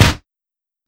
Kick (Cudi Zone).wav